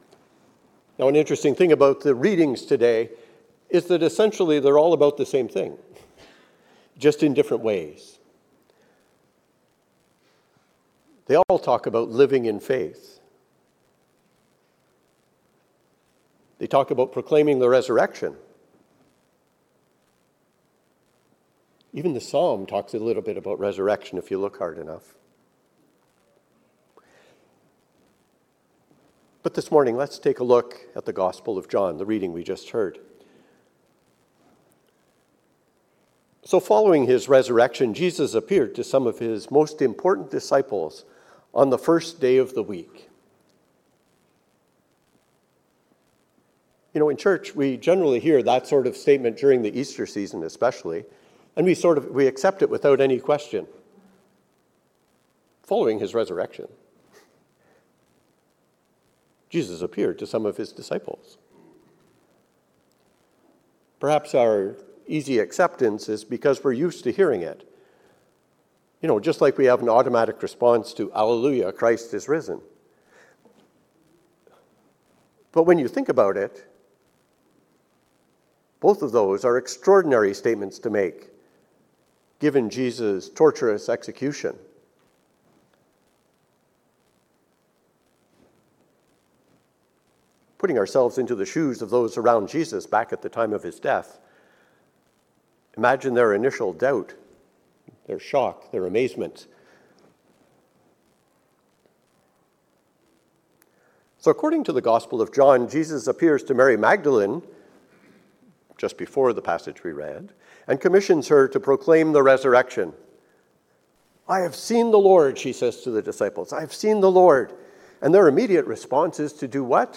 A sermon for the 2nd Sunday in Easter.